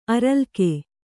♪ aralke